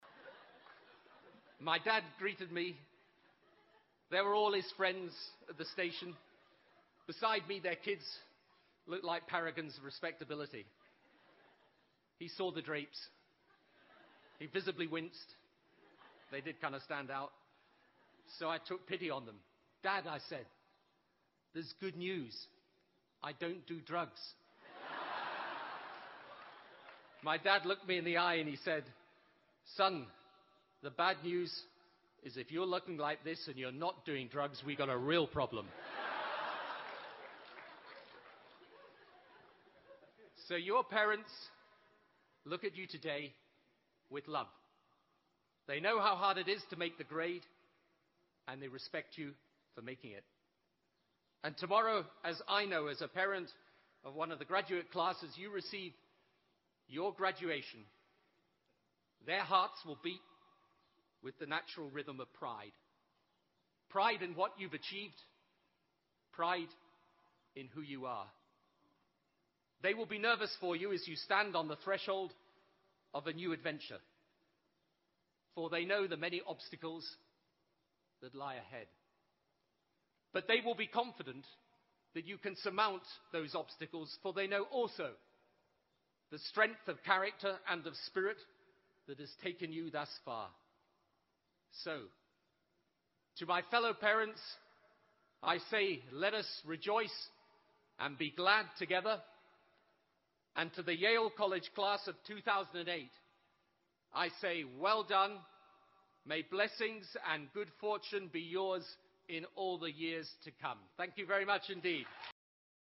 借音频听演讲，感受现场的气氛，聆听名人之声，感悟世界级人物送给大学毕业生的成功忠告。同时，你可以借此机会跟世界顶级人物学习口语，听他们的声音，模仿地道的原汁原味的腔调。